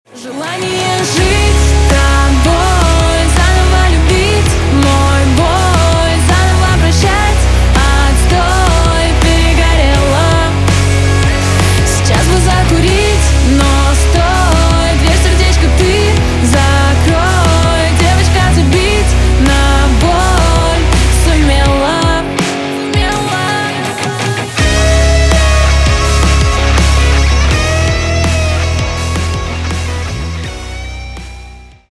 громкие
Pop Rock